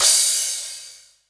• Long Urban Cymbal Sample G Key 03.wav
Royality free cymbal sample tuned to the G note. Loudest frequency: 7906Hz
long-urban-cymbal-sample-g-key-03-cjg.wav